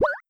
Water6.wav